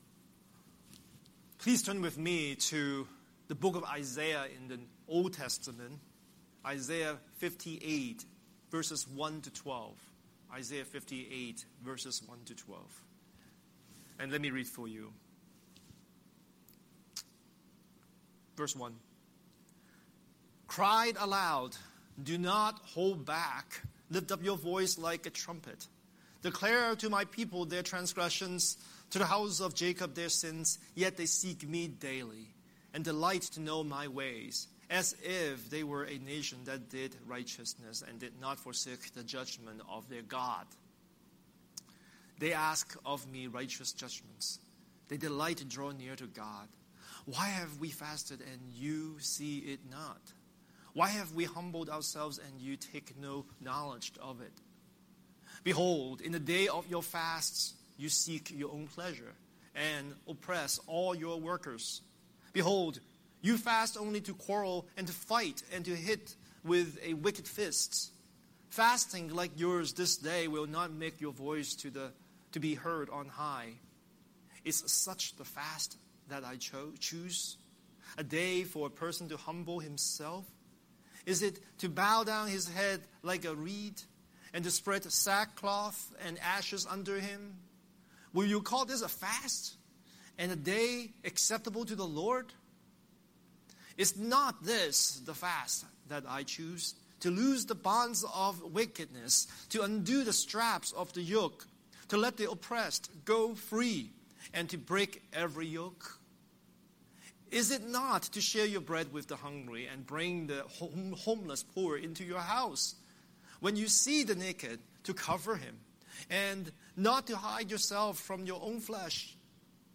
Series: Sunday Sermon